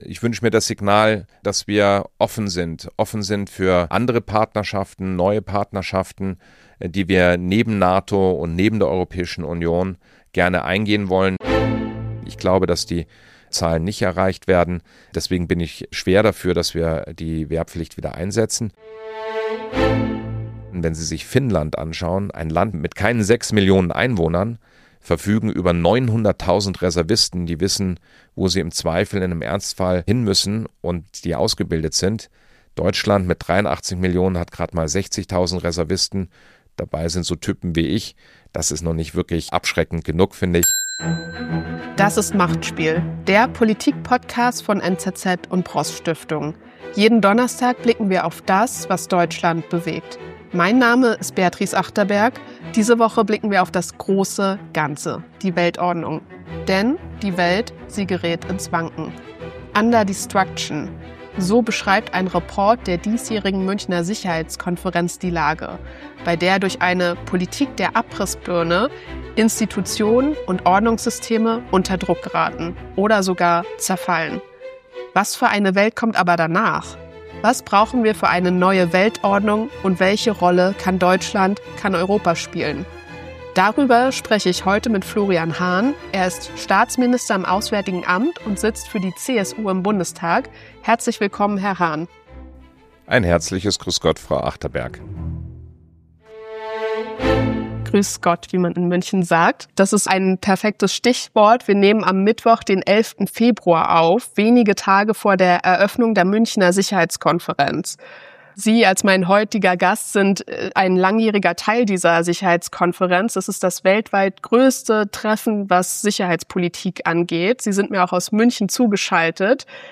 Gast: Florian Hahn, Staatsminister im Auswärtigen Amt